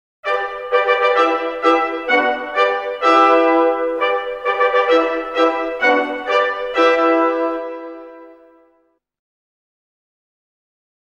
Ich finde, zu diesem feierlichen Ereignis darf gerne auch mal eine Fanfare ertönen: